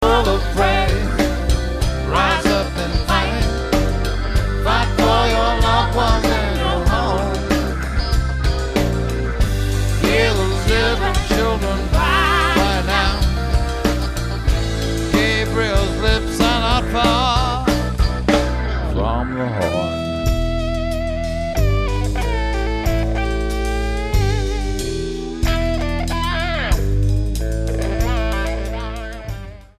STYLE: Rock
warm vibey music